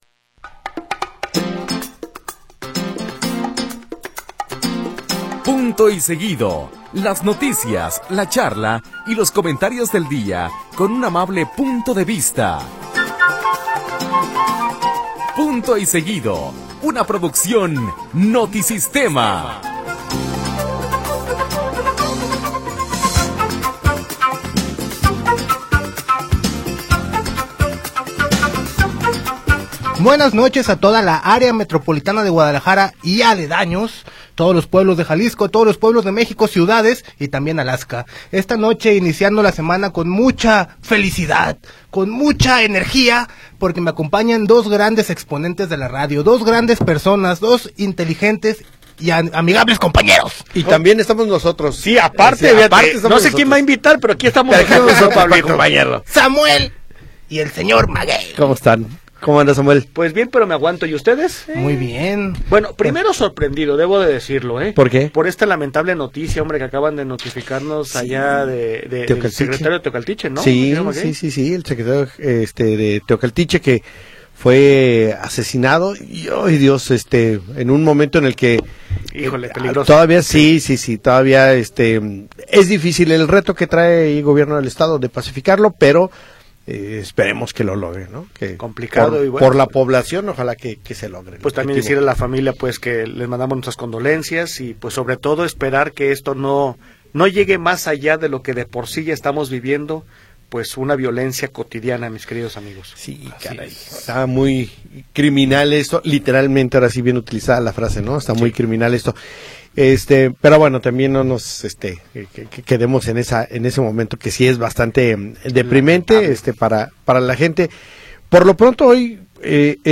… continue reading 26 Episoden # Notisistema # Ondas De Alegria # Unidifusion # Guadalajara # Discusión de Noticias # Países Bajos Noticias # JALISCO